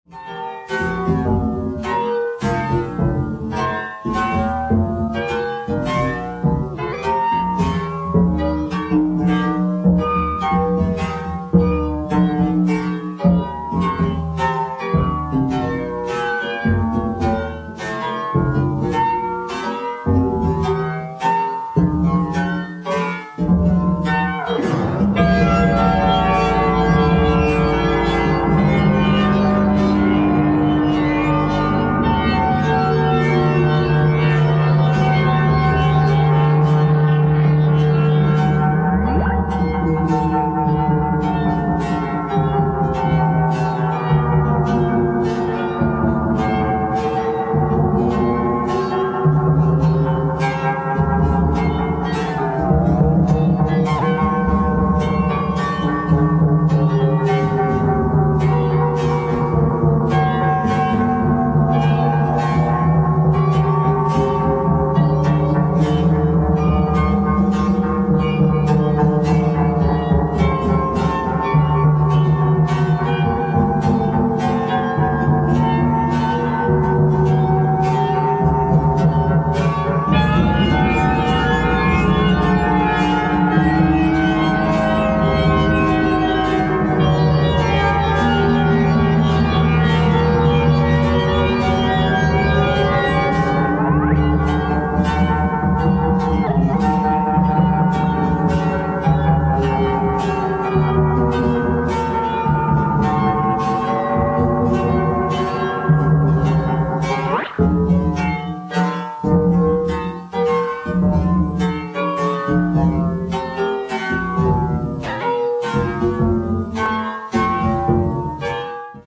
the maverick master of British Experimental Music.